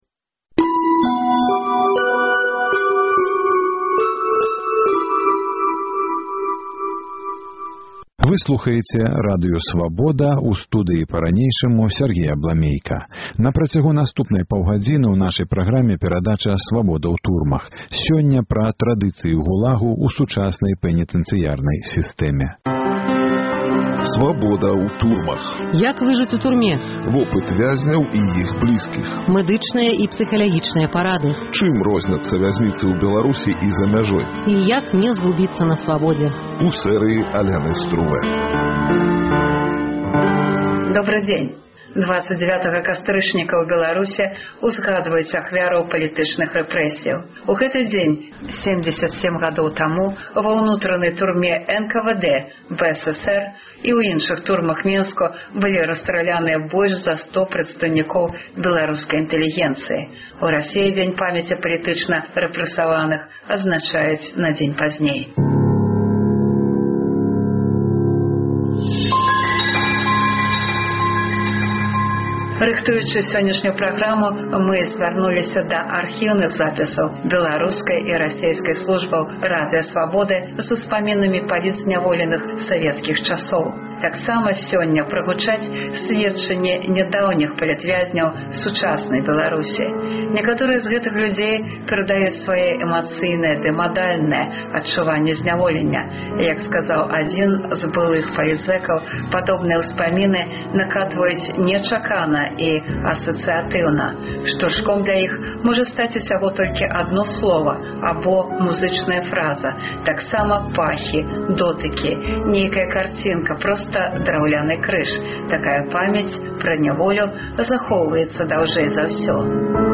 У перадачы гучаць успаміны вязьняў савецкіх лягераў, а таксама сьведчаньні нядаўніх палітзьняволеных сучаснай Беларусі.